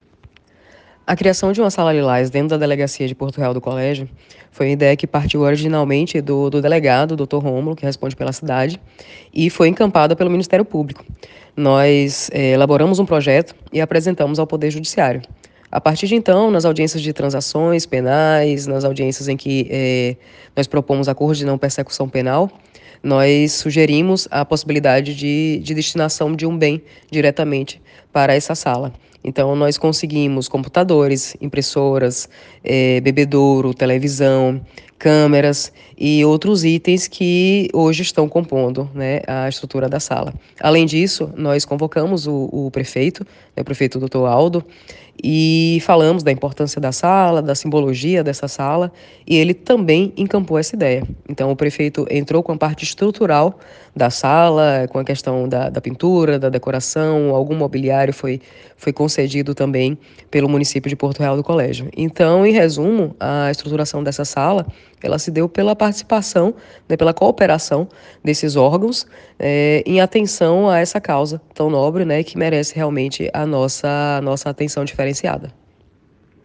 Promotora-de-Justica-Ariadne-Dantas.ogg